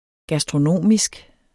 Udtale [ gasdʁoˈnoˀmisg ]